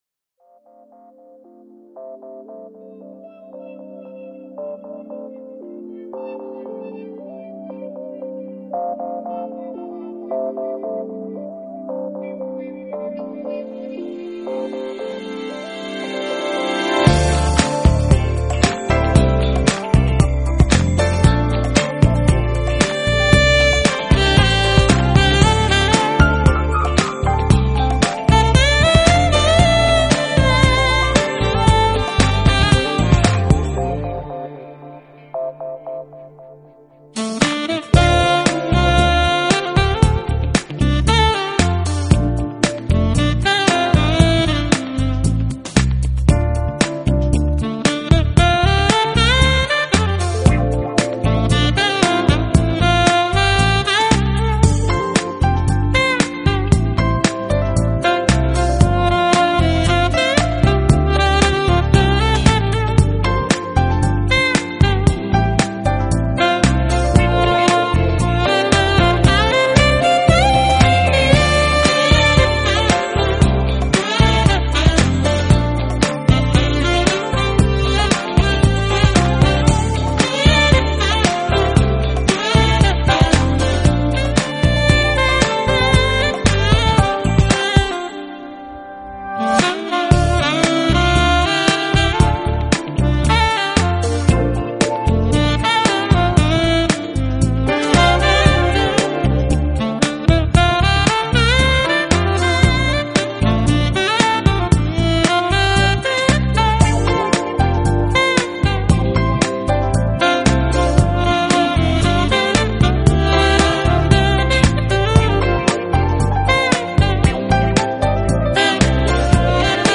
音乐类型：Jazz,Saxophone